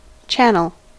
Ääntäminen
US : IPA : [ˈtʃæn.əl]